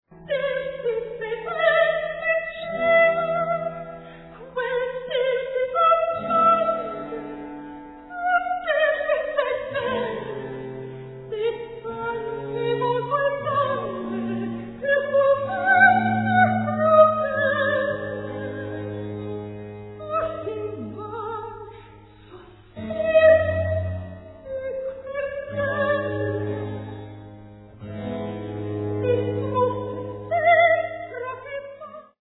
soprano
cello